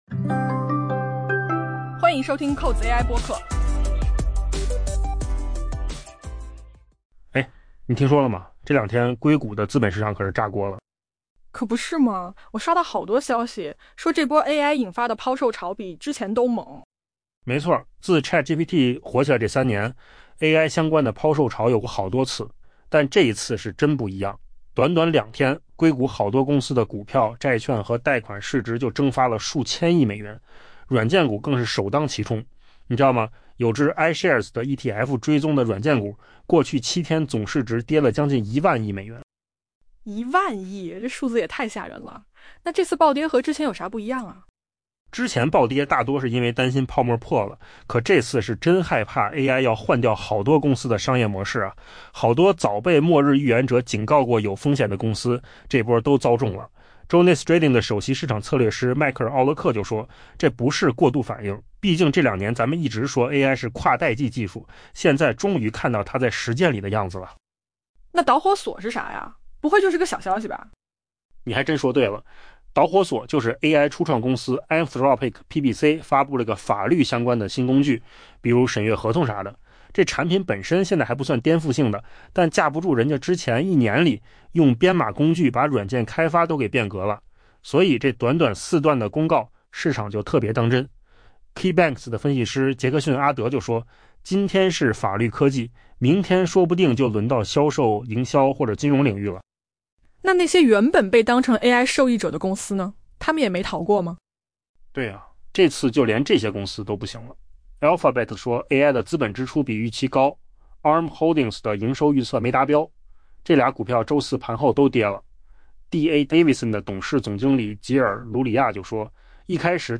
AI播客：换个方式听新闻